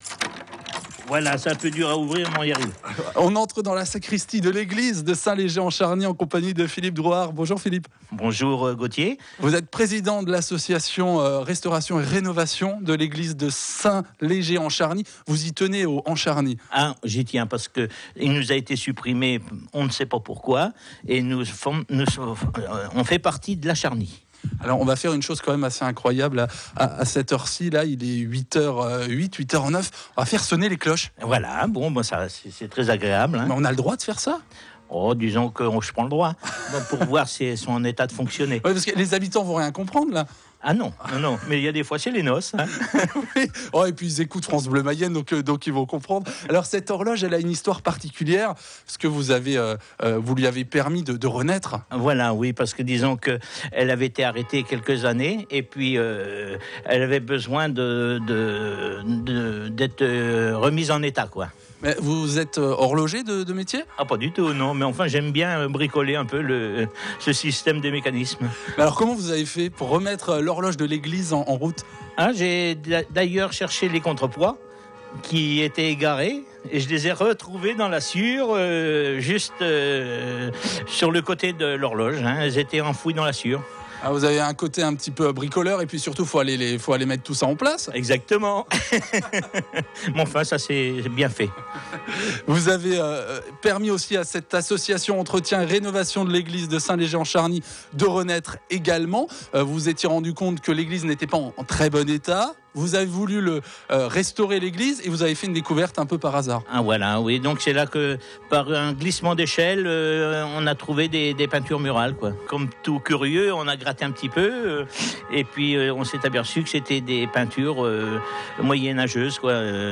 interviewé en 2014 dans l'église